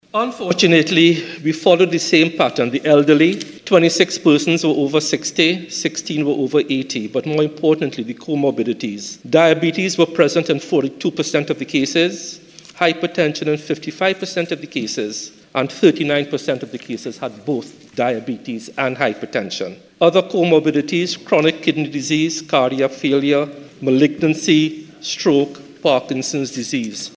This was revealed in the Parliament today by Health Minister Terrence Deyalsingh, who said the deaths continue to be elderly and at risk persons.
Health Minister Terrence Deyalsingh.